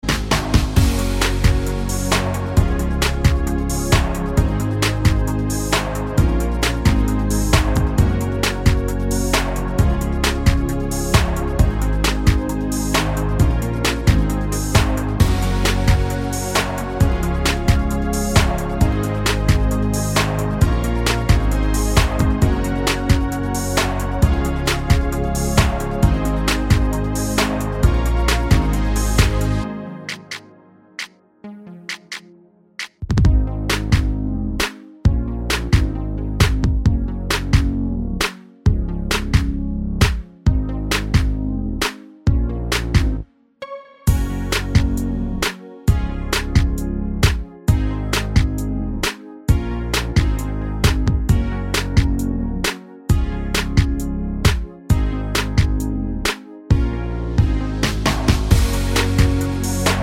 With Intro and No Backing Vocals Pop